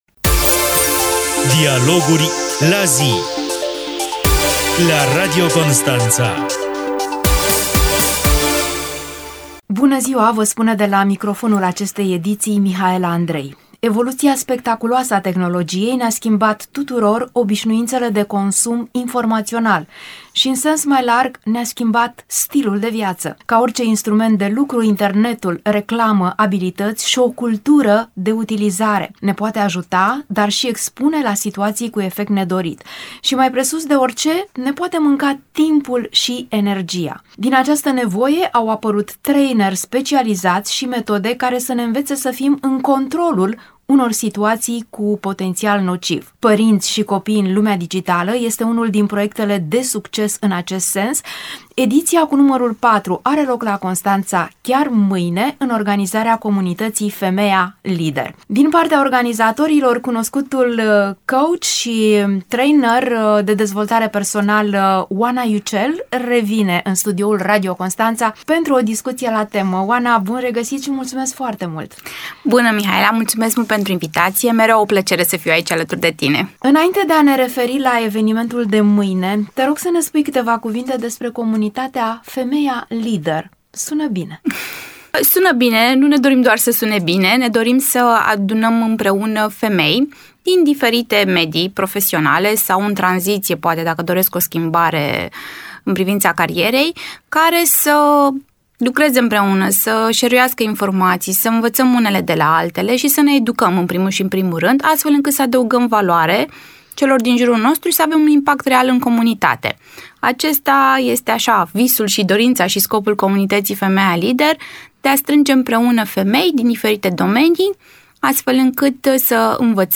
emisiunea